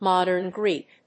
アクセントMódern Gréek